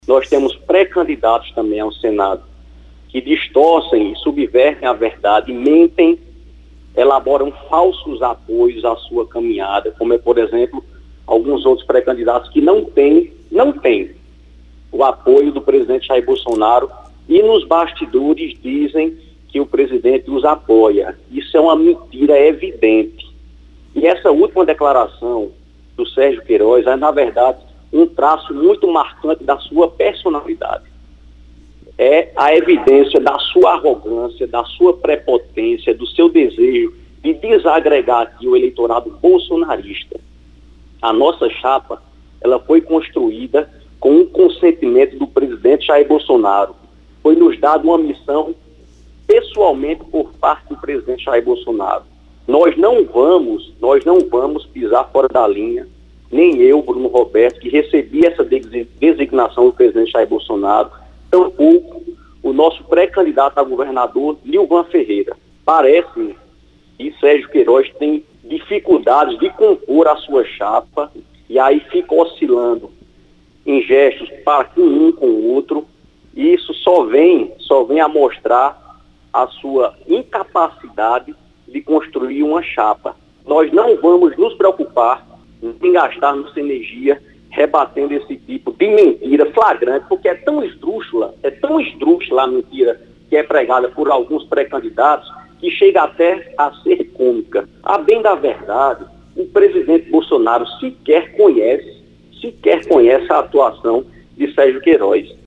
foi registrado nesta segunda-feira (30/05) pelo programa Correio Debate, da 98 FM, de João Pessoa.